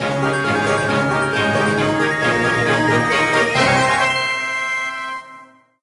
reveal_epic_card_01.ogg